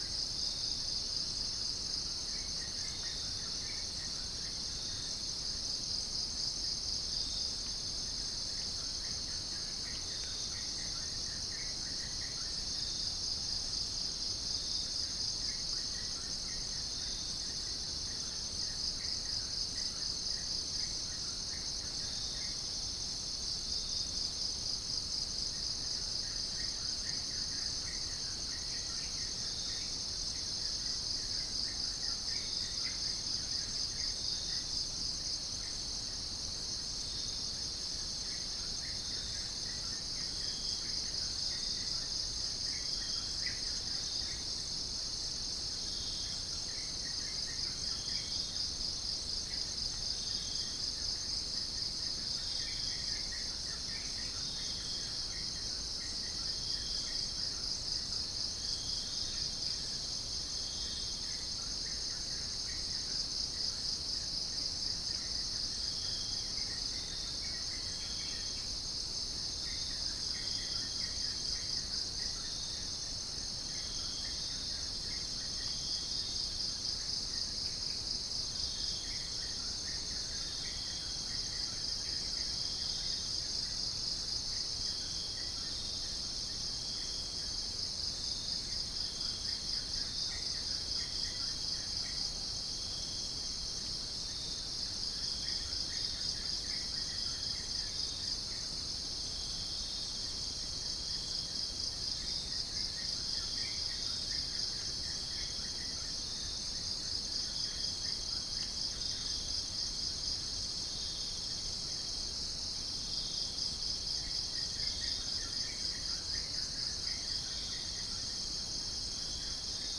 Gallus gallus
Pycnonotus goiavier
Prinia familiaris